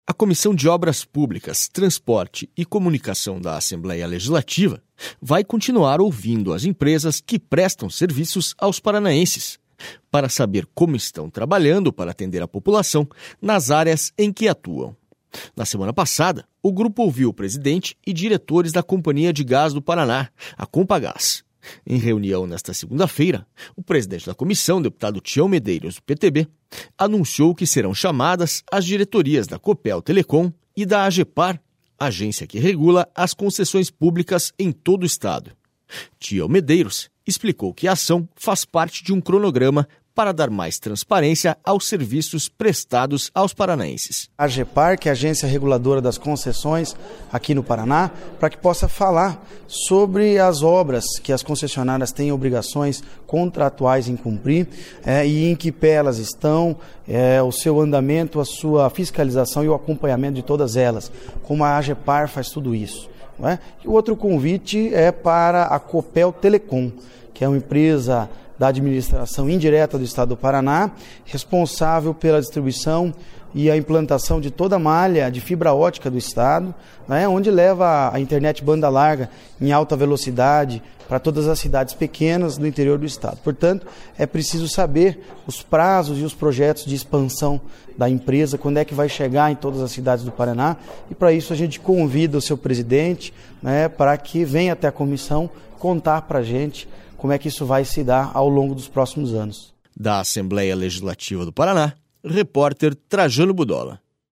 SONORA TIÃO MEDEIROS